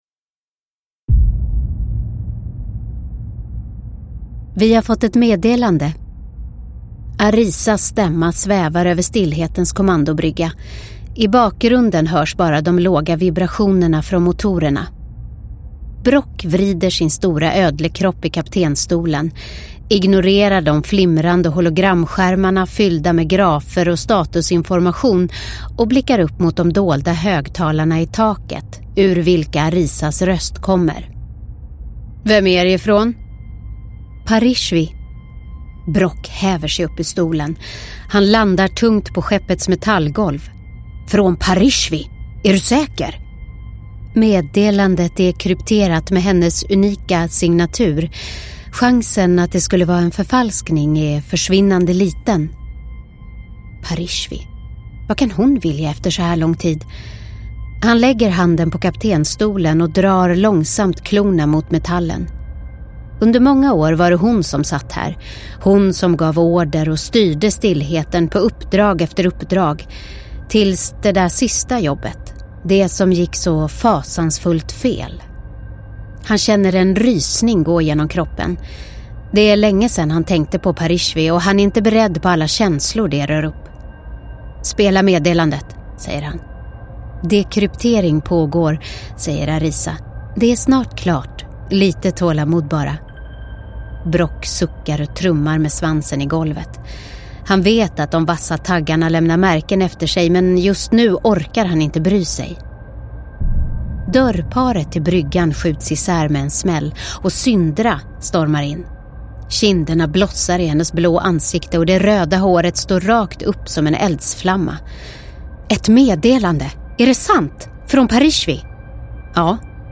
Bortförda – Ljudbok – Laddas ner